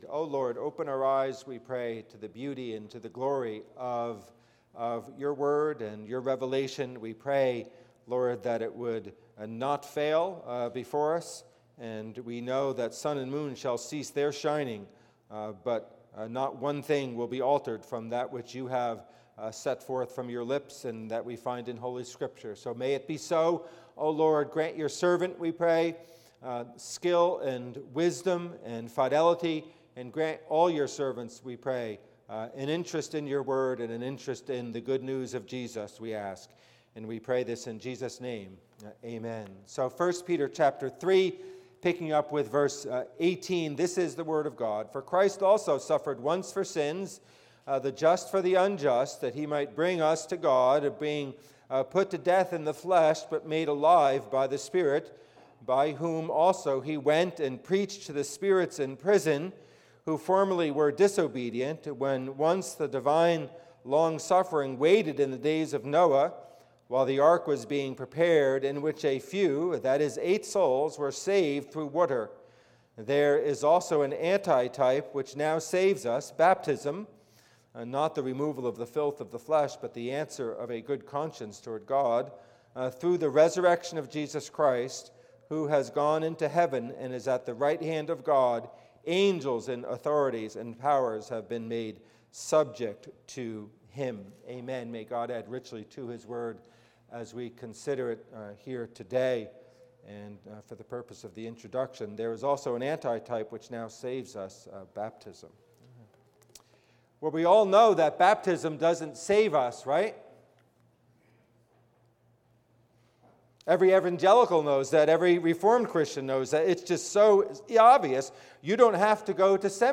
Passage: 1 Peter 3:18-22 Service Type: Worship Service